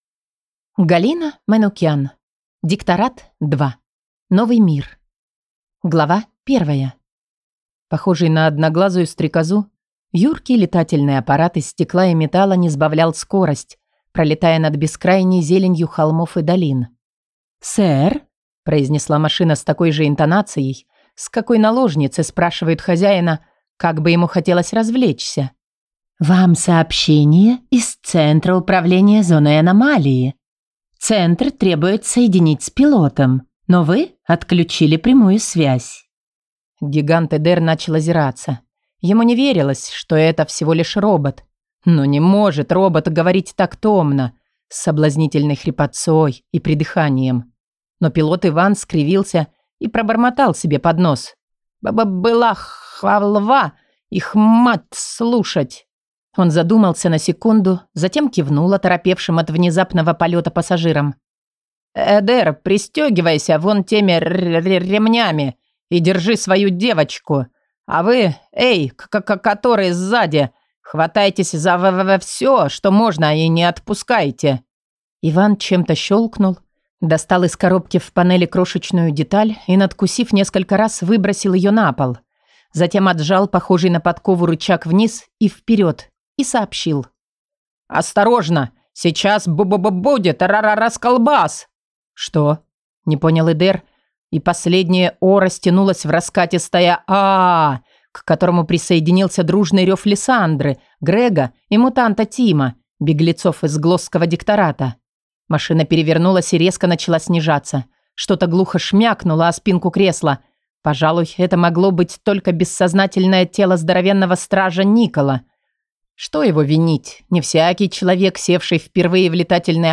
Аудиокнига Дикторат. Эффект молнии. Часть 2 | Библиотека аудиокниг